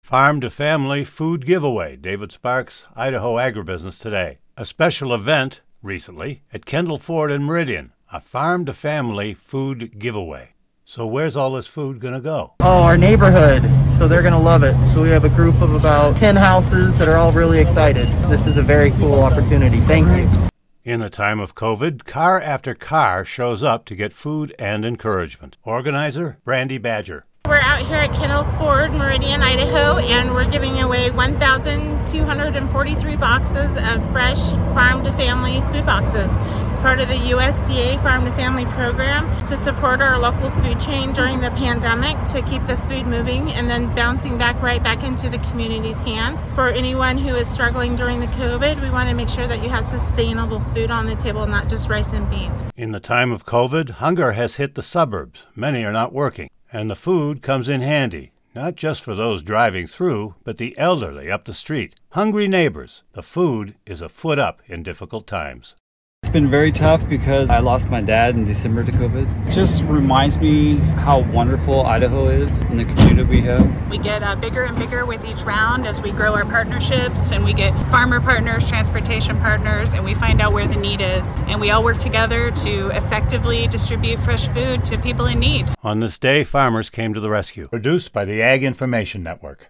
A special event at Kendal Ford in Meridian.